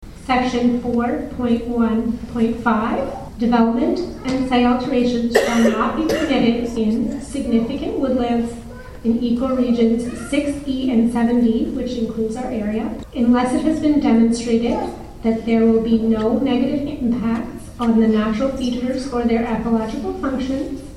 The matter was addressed at a Public Meeting of the Committee at the Nick Smith Centre in Arnprior February 5th.
While the meeting audio remains difficult, it is nowhere discernable that there was mention of the 2022 “More Homes Built Faster Act” as having any bearing on the appeal process- although that legislation has impacted an Ottawa group that was in the process of objecting to redevelopment at Lansdowne Park.